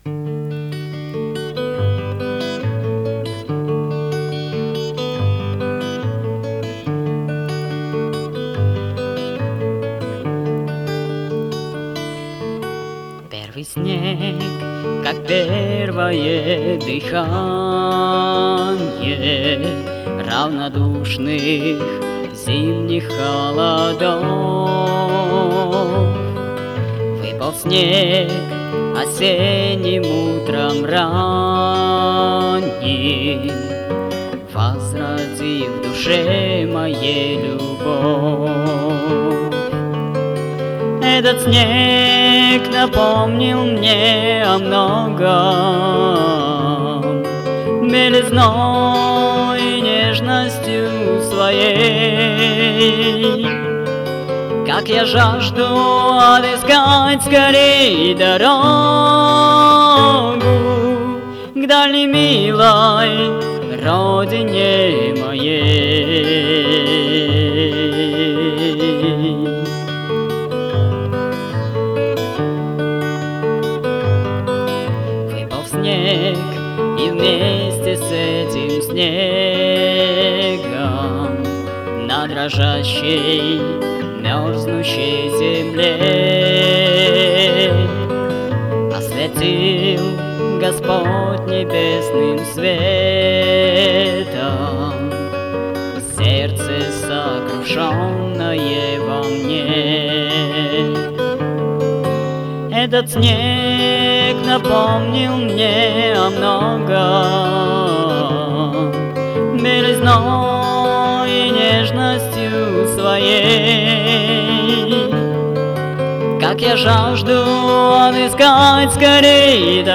Альбом записан в Христианском Культурном центре.